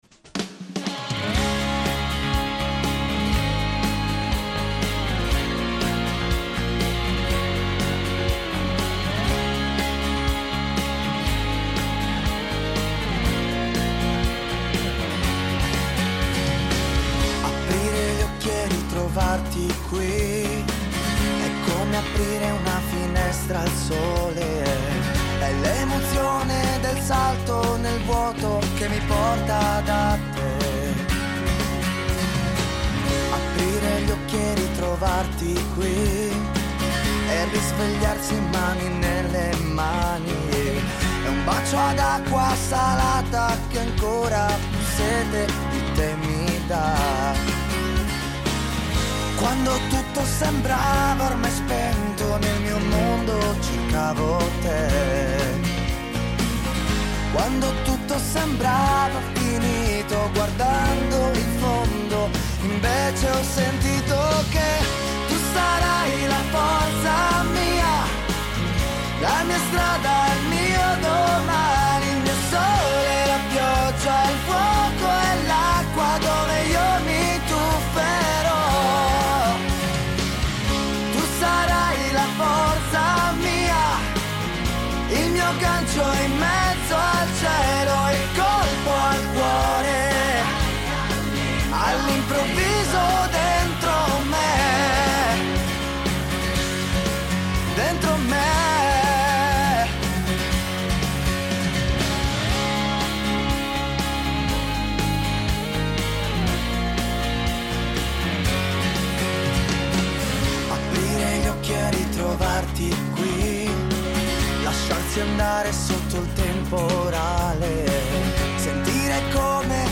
Incontro con Marco Carta, giovane cantante